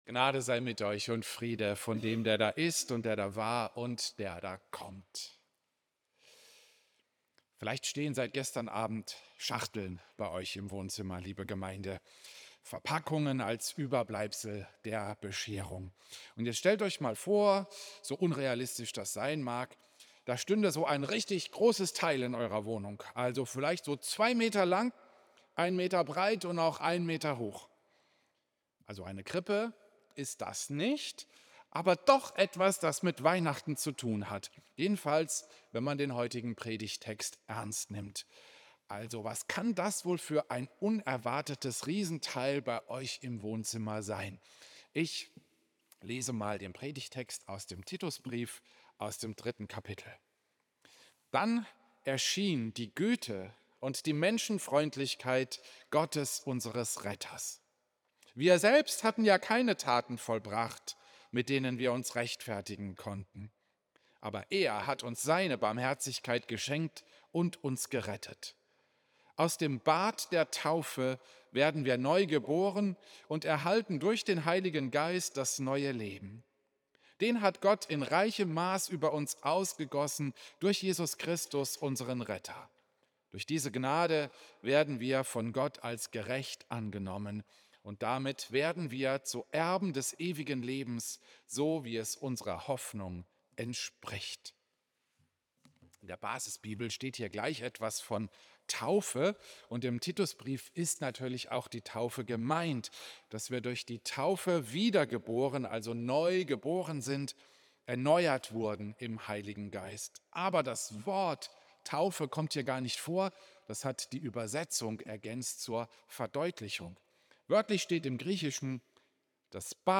Klosterkirche Volkenroda, 25. Dezember 2025
Predigten